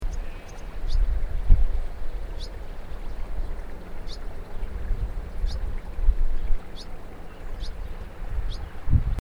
barn swallow
Barn-Swallow-Hirundo-rustica.mp3